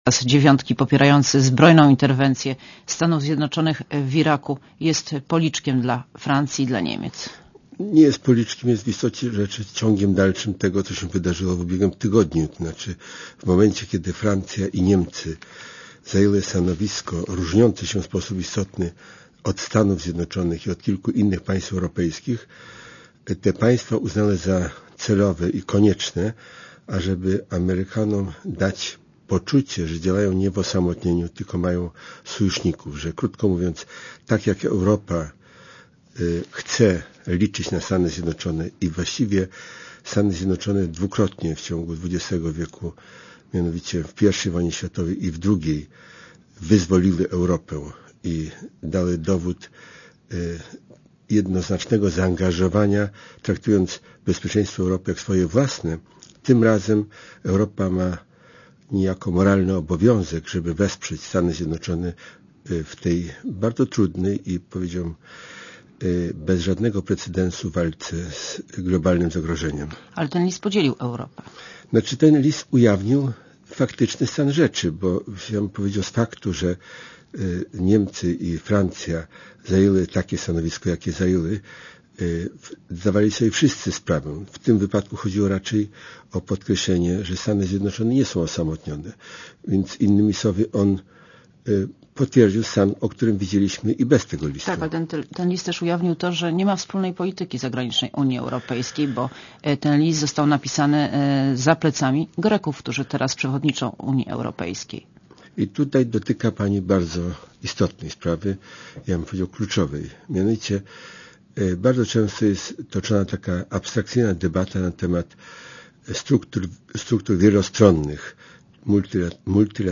Monika Olejnik rozmawia z Adamem Danielem Rotfeldem - wiceministrem spraw zagranicznych